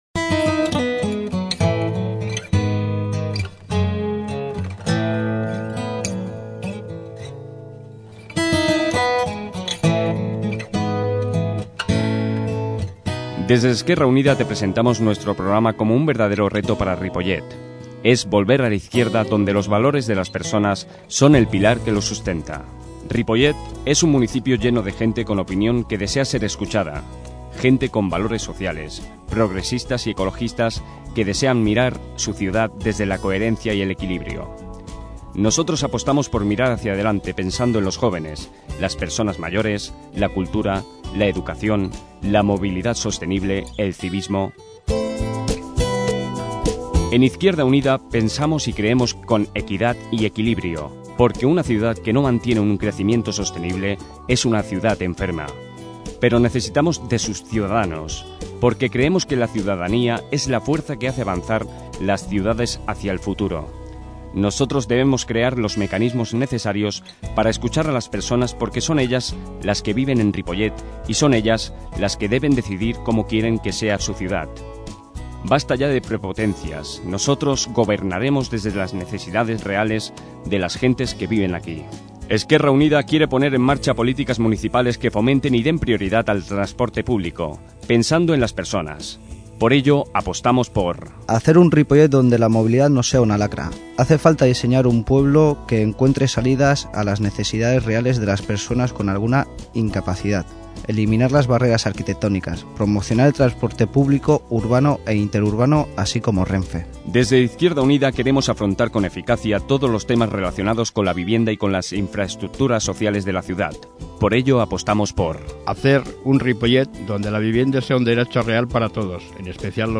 Política MUNICIPALS 2007 - Espai de propaganda d'EU-IU -Política- 14/05/2007 Descarregueu i escolteu l'espai radiof�nic enregistrats per aquest partit pol�tic de Ripollet a l'emissora municipal.